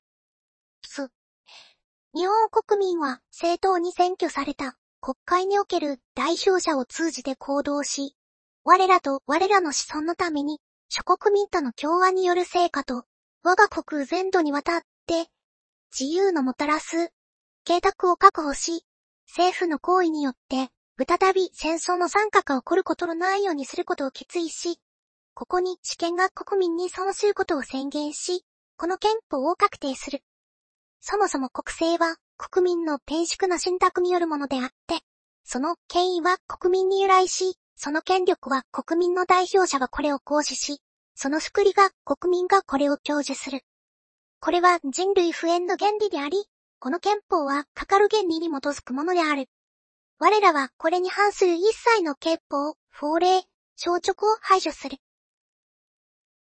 training_03_df50_sim1.mp3：上記と同じトレーニング結果に対して、Diffusion Stepsを50に、Similarity CFG Rateを1にした例
また、おまけ」のほうは高音域が削除されているせいか全体的に落ち着いた声になりました。
最初に「つっ」とか発音している部分がありますが、これは入力音声の無音の部分（もしくは小さな雑音が入っている部分）も変換しているためです。